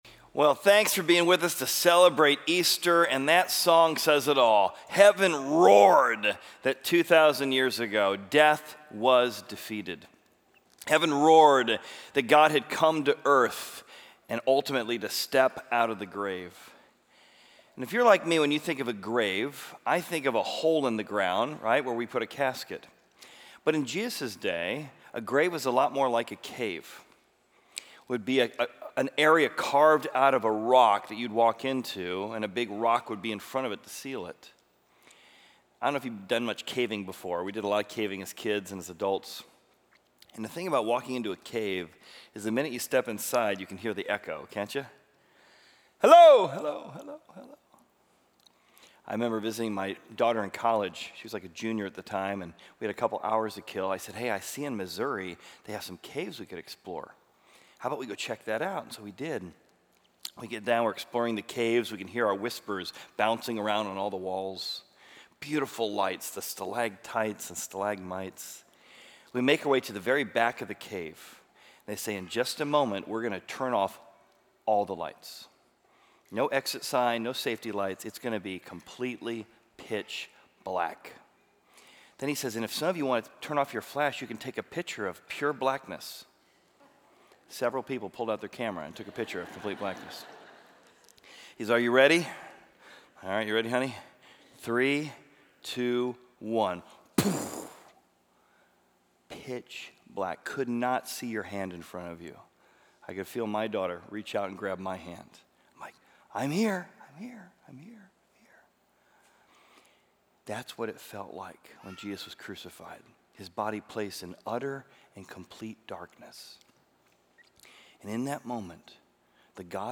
Join Horizon Community Church for an Easter Celebration Service! This service will be a meaningful and triumphant celebration of Christ’s resurrection, highlighted by worship music.